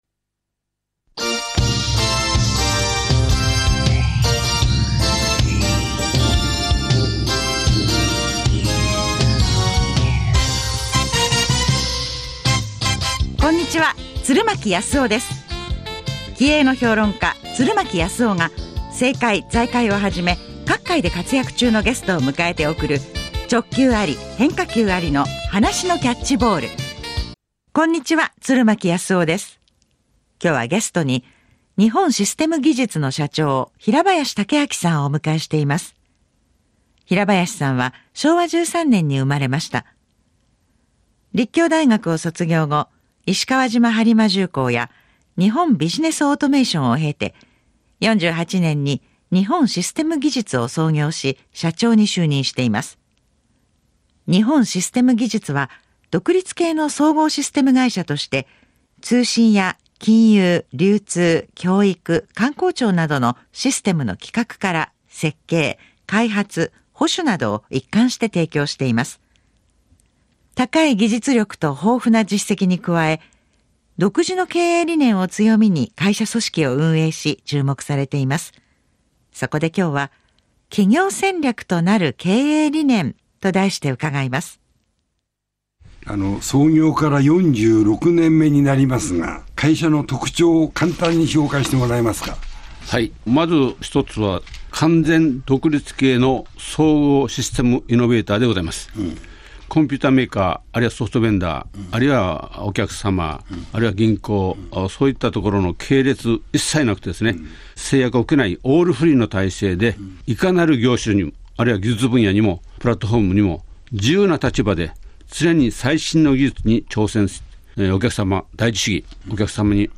ラジオ日本番組出演のお知らせ